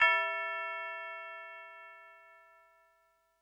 DP Bell.wav